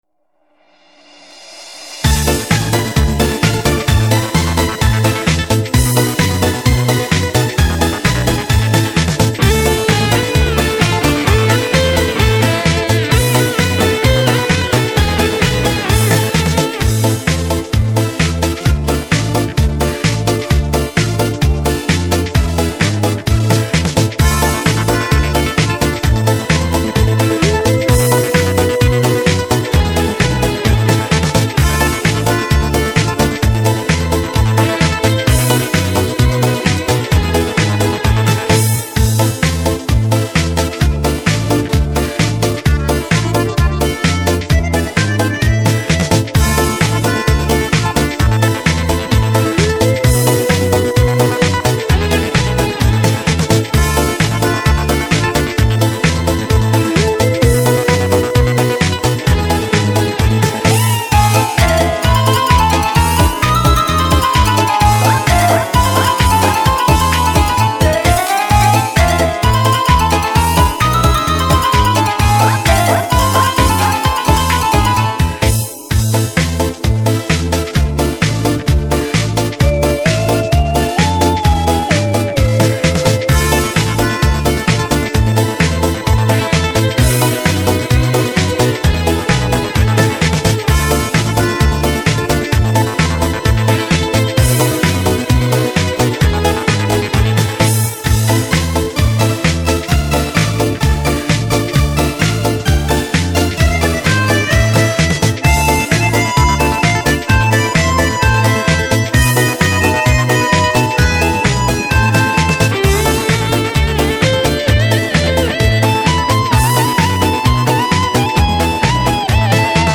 Категорія: Мінусовки